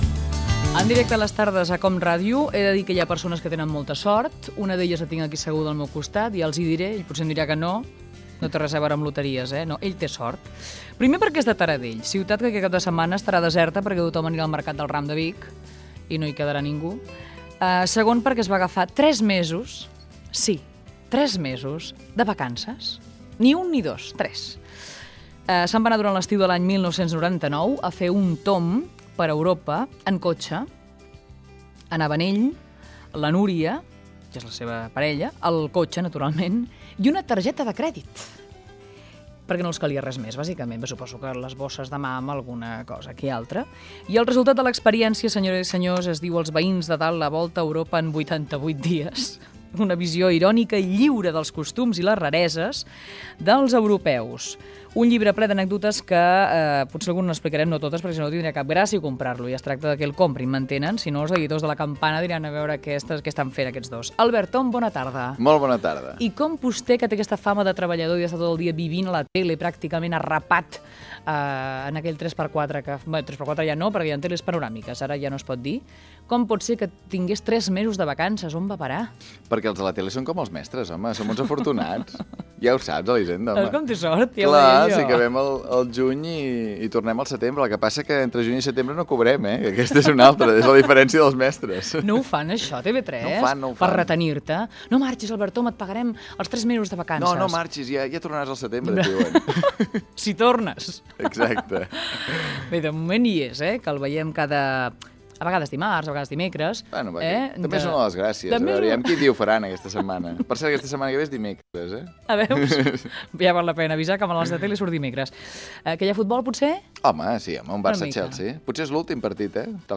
Fragment d'una conversa amb el periodista i escriptor Albert Om, autor del llibre "Els veïns de dalt. La volta a Europa en 80 dies"
Entreteniment